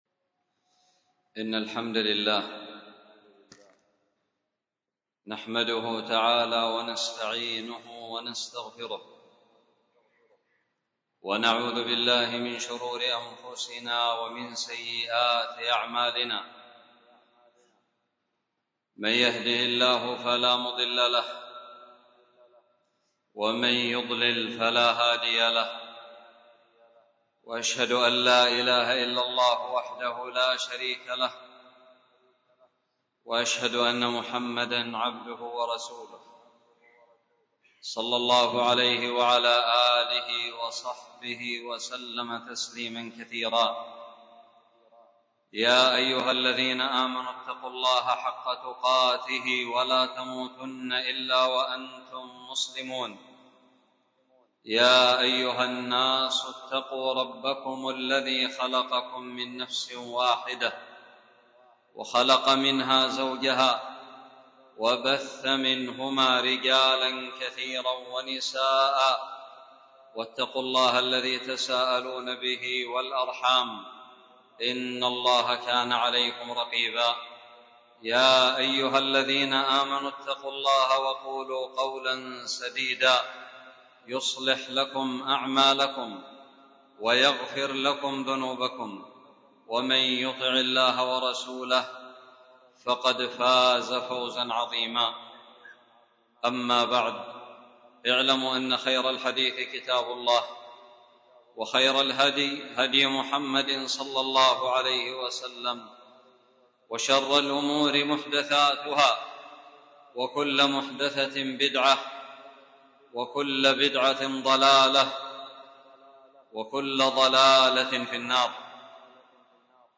خطب الجمعة
ألقيت في حضرموت غيل باوزير مسجد باهارون